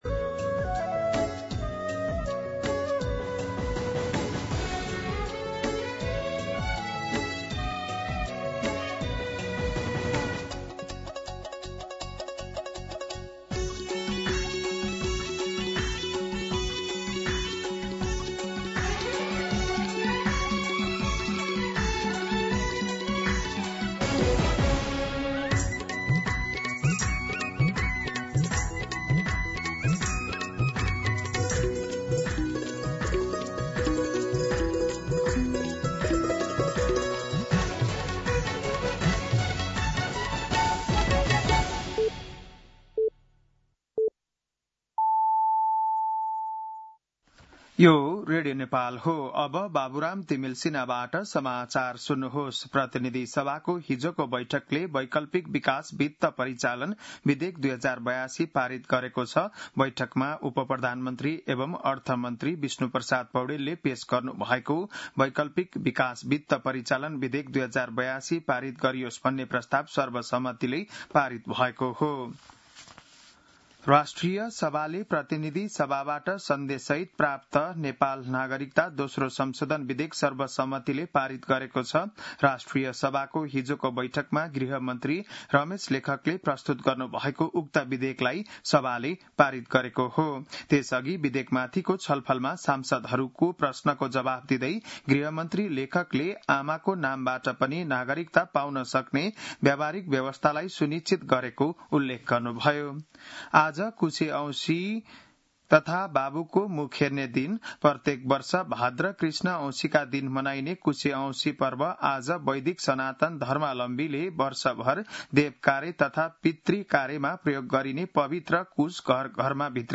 बिहान ११ बजेको नेपाली समाचार : ७ भदौ , २०८२
11-am-Nepali-News-6.mp3